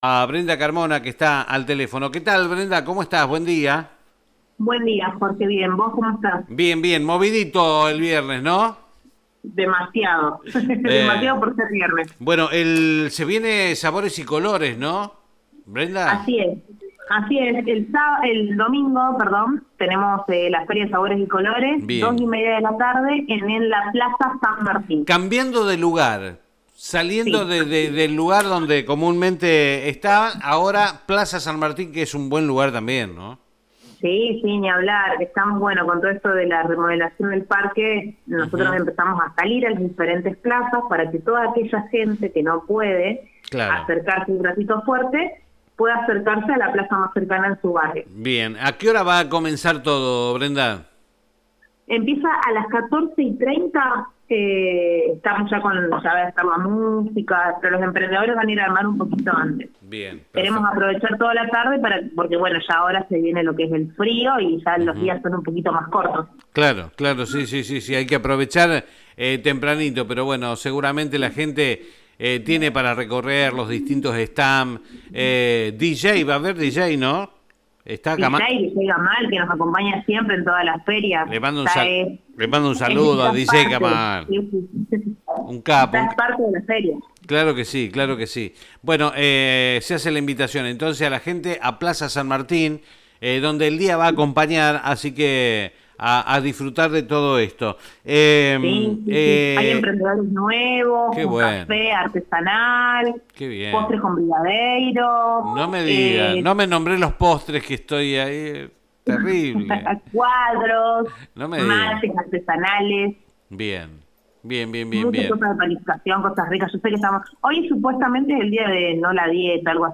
en comunicación telefónica